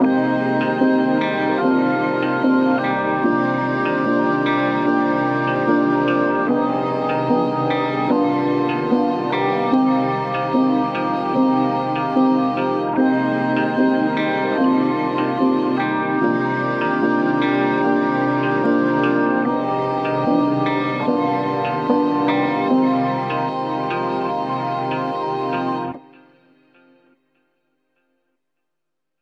Loop pack for dark melodies like Dark R&B, Trap, Dark Pop and more.
Classic synth tones that add that special and organic textures to your songs.
• High-Quality RnB Samples 💯